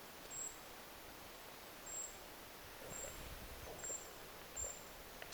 kuvien puukiipijän ääntä ilmeisestikin
ilm_kuvien_puukiipijalinnun_aanta.mp3